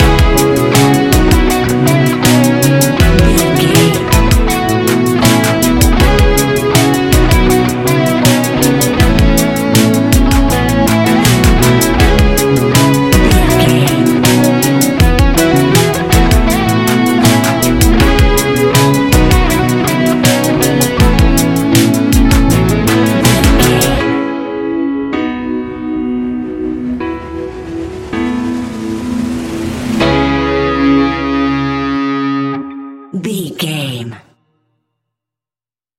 Ionian/Major
ambient
electronic
downtempo
space music